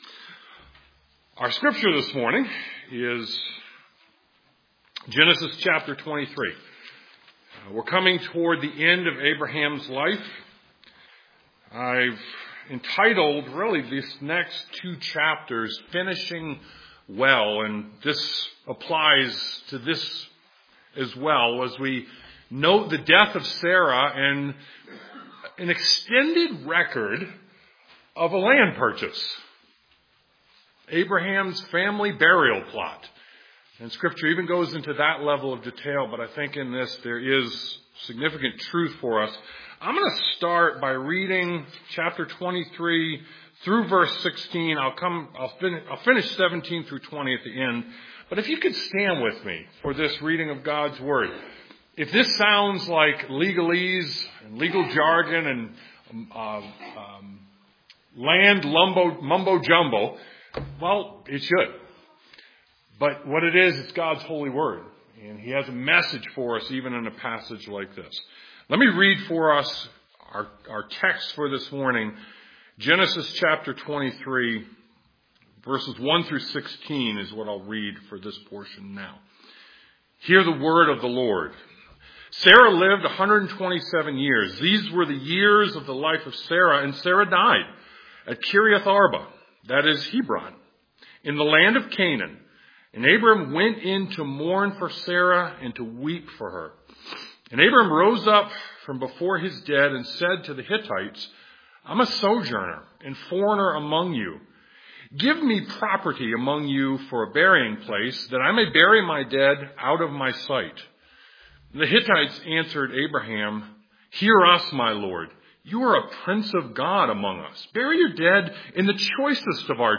Genesis 23:1-20 Service Type: Sunday Morning Genesis 23:1-20 Finishing well involves everyday life